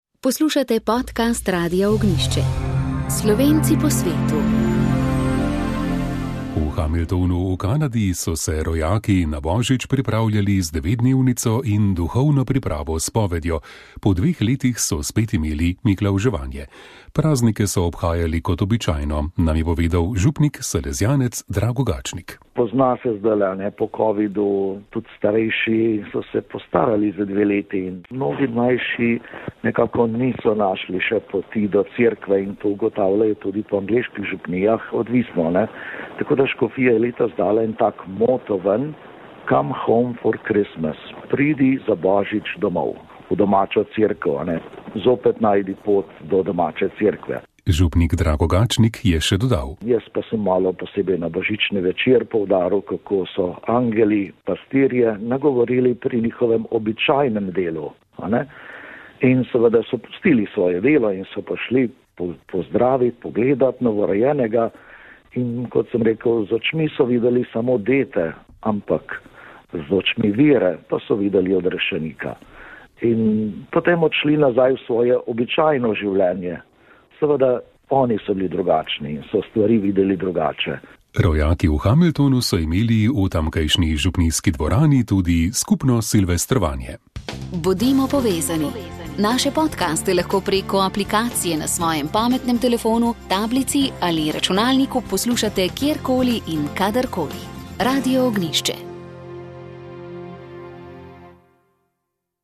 Komentar tedna